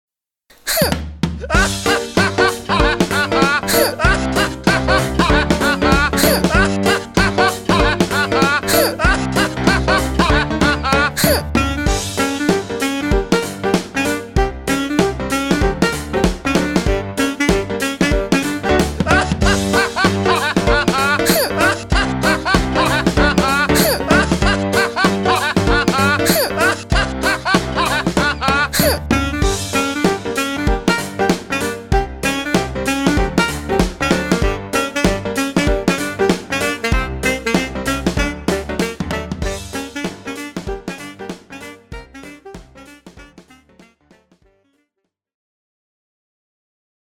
男女声哼啊哈哈哈配乐音效免费音频素材下载